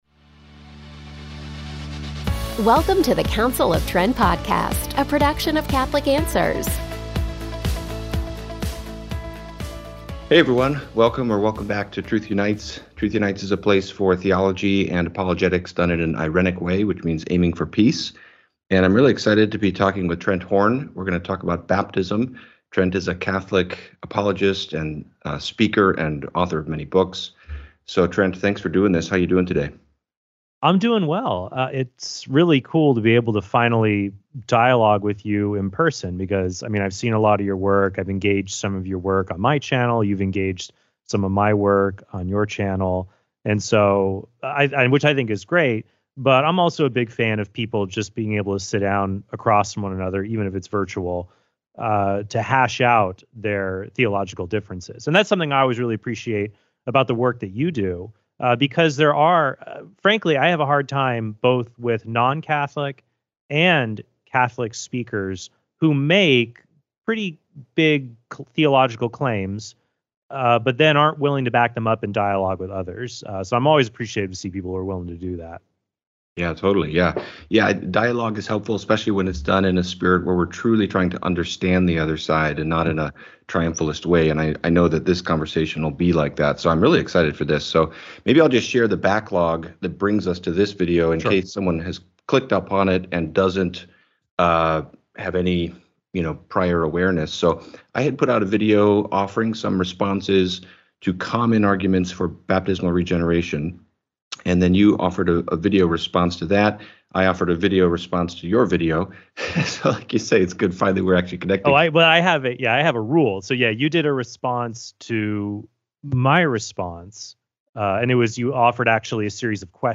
DIALOGUE